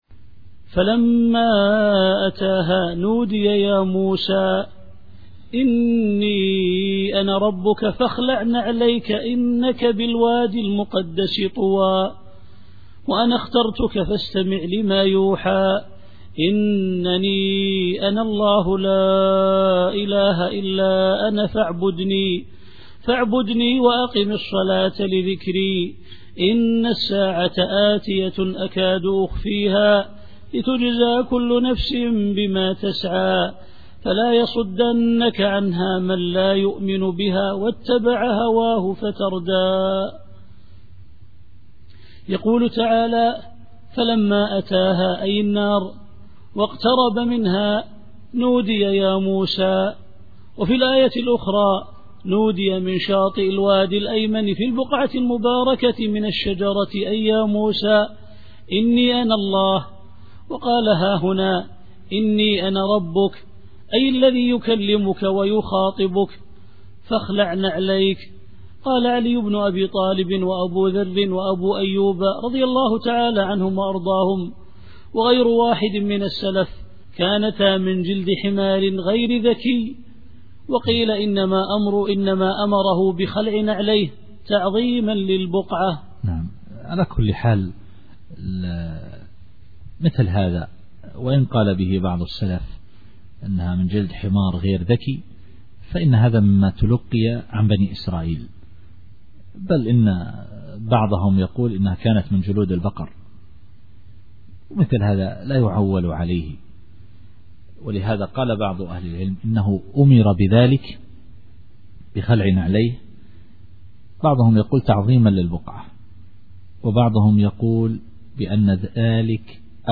التفسير الصوتي [طه / 11]